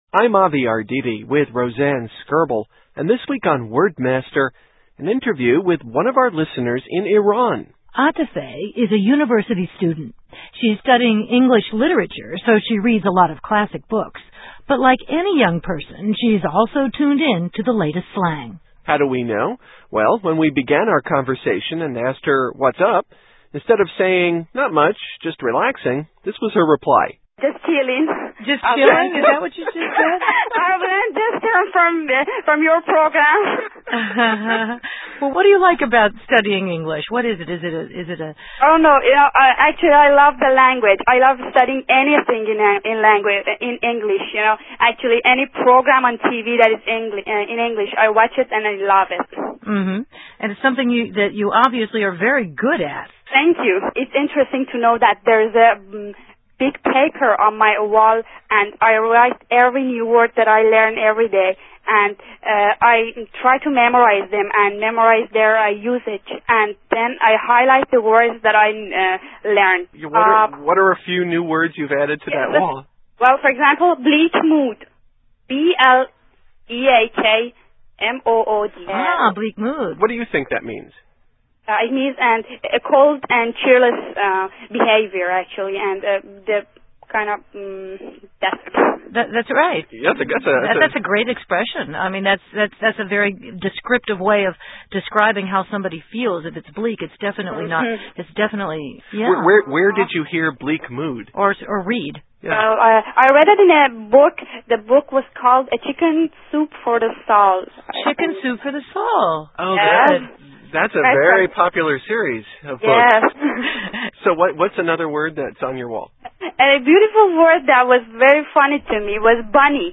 March 9, 2005 - Interview with an English Learner in Iran